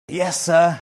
Sir can be pronounced strongly, when used as a term of address rather than as a title. In England it then has the long vowel əː (often written ɜː).